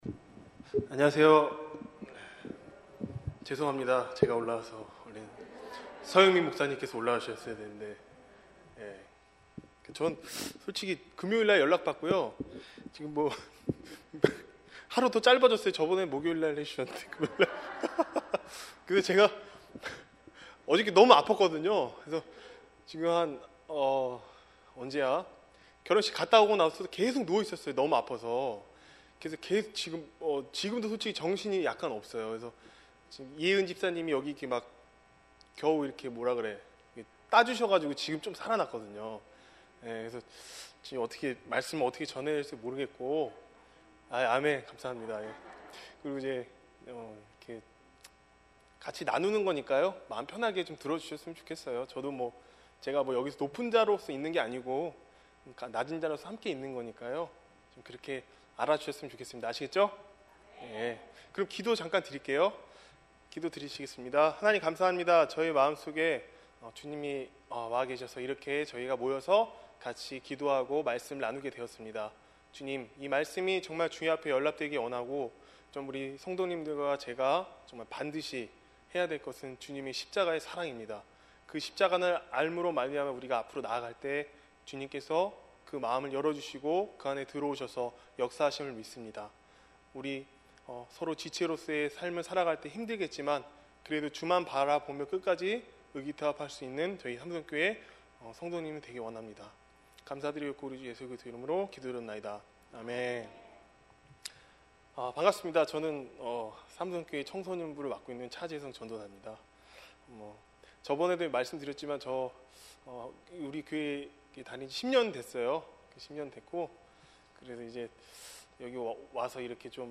주일예배 - 야고보서 4장 1절-10절